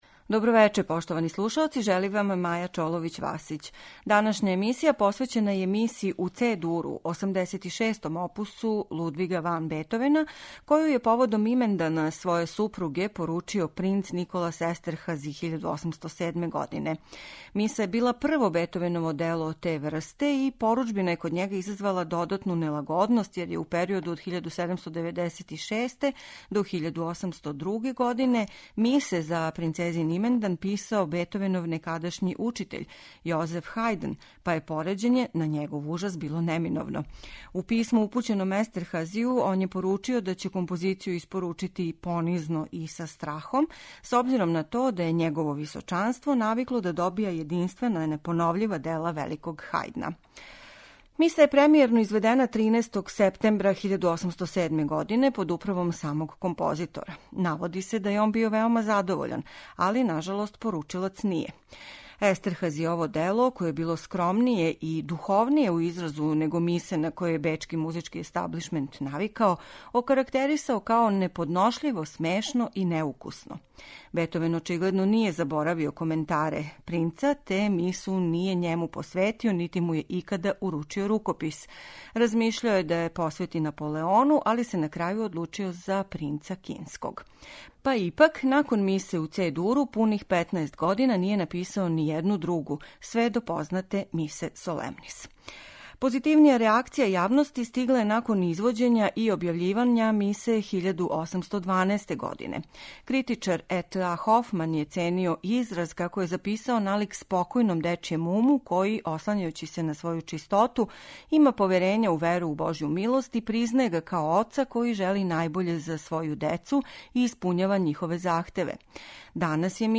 Емитоваћемо снимак хора Монтеверди и Револуционарног и романтичног оркестра. Диригент је Џон Елиот Гардинер.